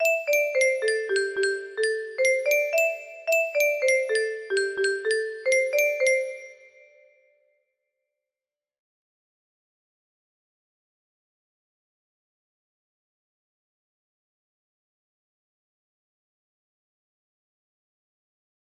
Jingle two music box melody